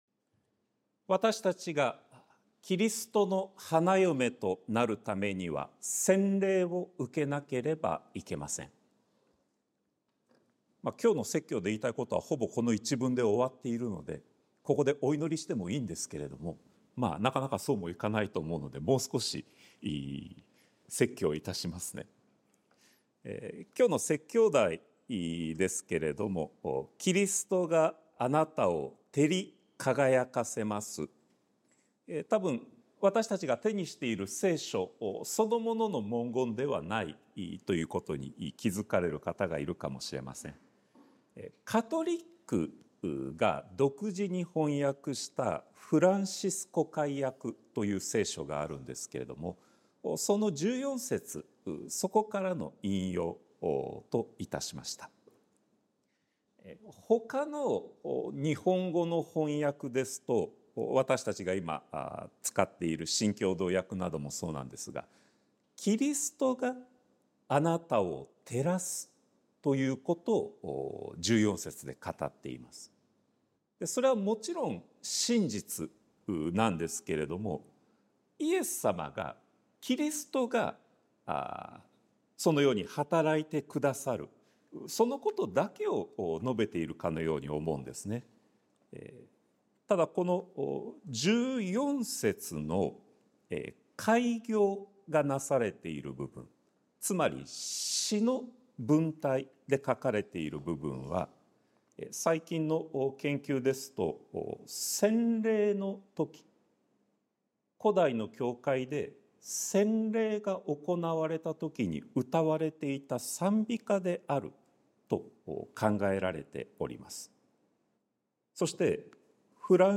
sermon-2024-08-25